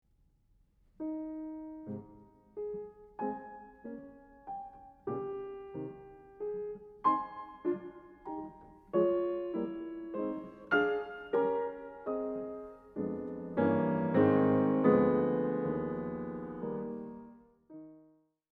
The fourth variation is almost ballet-like.
The short chords in the left hands hangs in the air, and we hear just as much silences as we hear sounds.